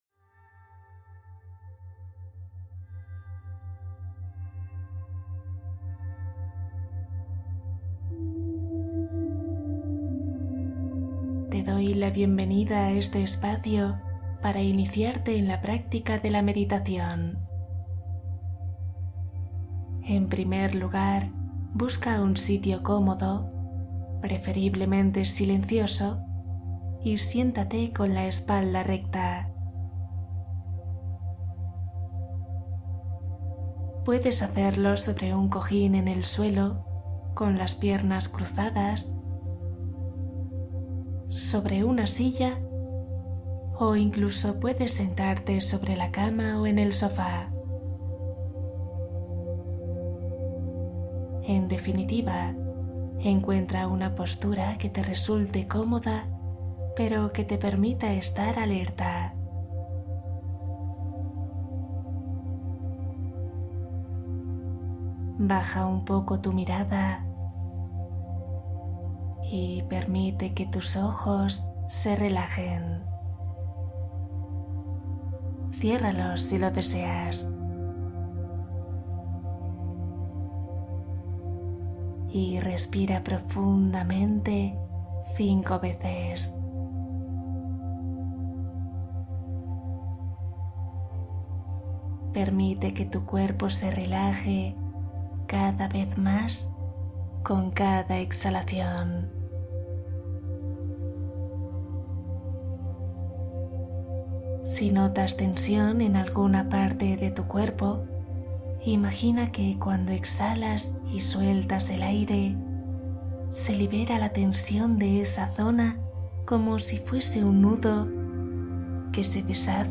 Despierta con energía ❤ Meditación guiada para las mañanas